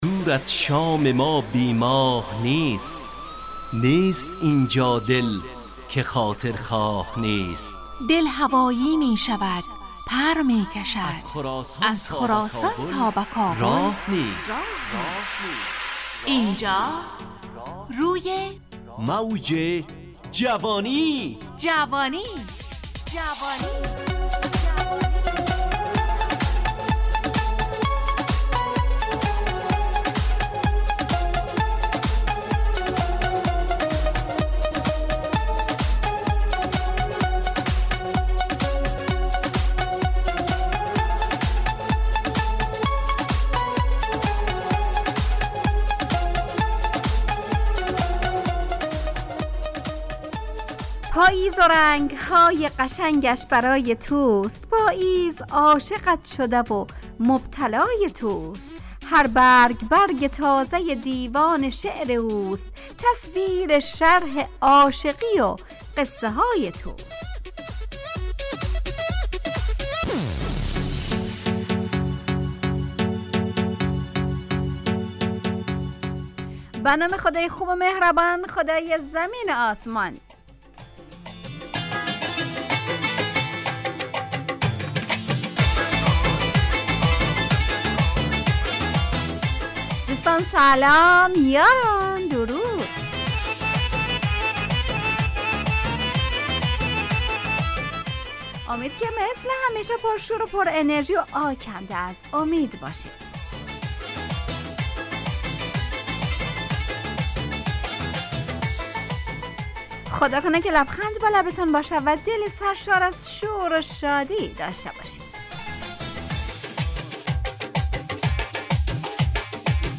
همراه با ترانه و موسیقی مدت برنامه 55 دقیقه . بحث محوری این هفته (ظاهر و باطن ) تهیه کننده